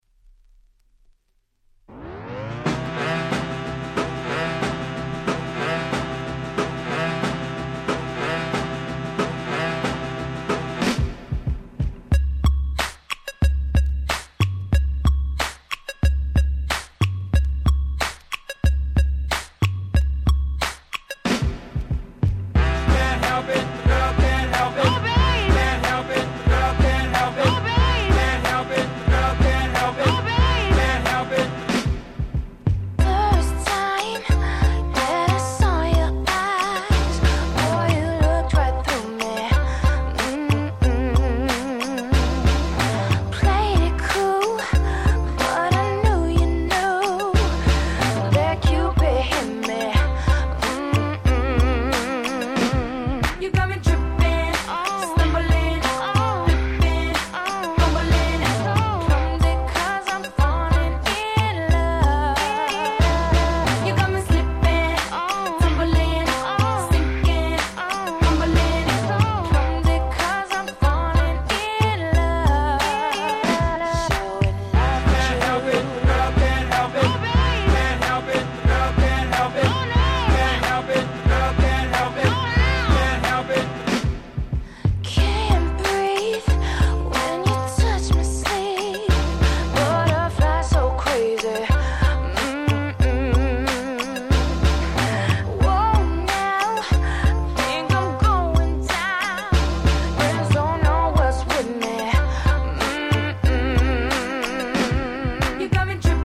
06' Super Hit R&B !!